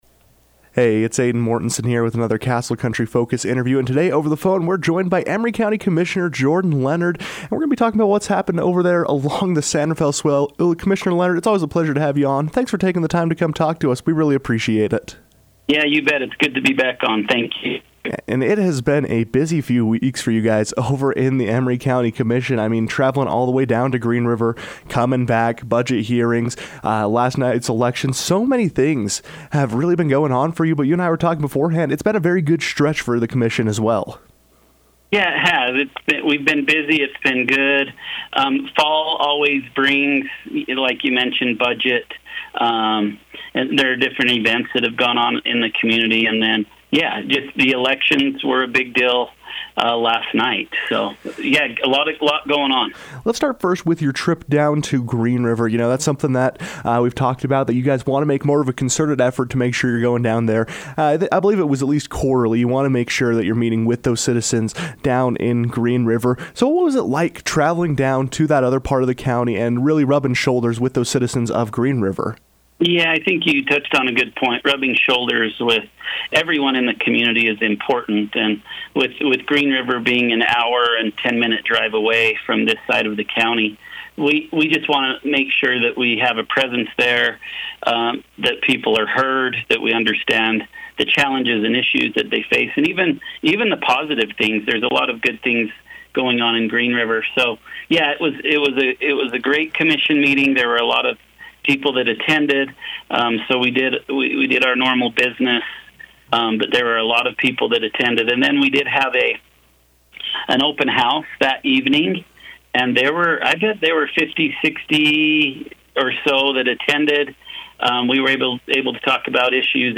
As local governments prepare for year-end, Emery County Commissioner Jordan Leonard joined the KOAL newsroom to discuss what's happening along the San Rafael Swell and the preparations the commission is looking to complete as 2026 rapidly approaches.